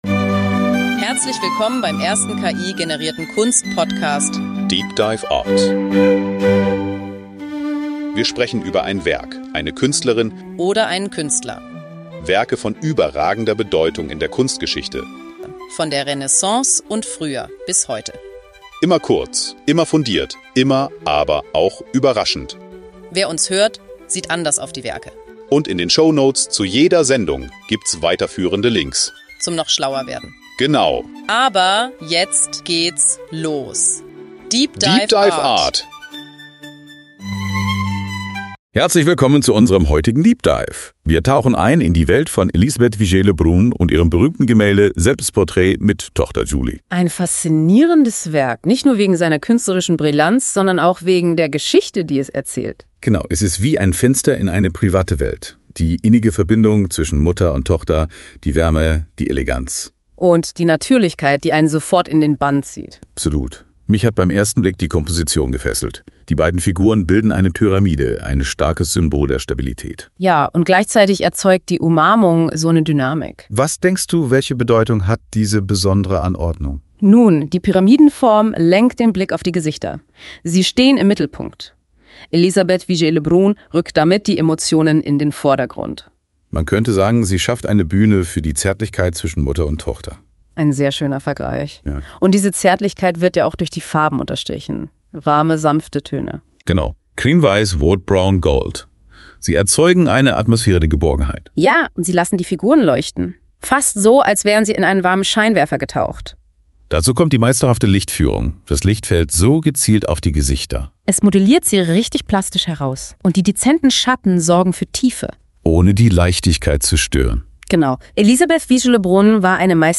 Die Verbindung zwischen Mutter und Tochter wird als zentrales Thema hervorgehoben, während die Rolle der Frau in der Kunst und Gesellschaft kritisch betrachtet wird. deep dive art ist der erste voll-ki-generierte Kunst-Podcast.
Die beiden Hosts, die Musik, das Episodenfoto, alles. dda ist für alle, die wenig Zeit haben, aber viel Wissen wollen.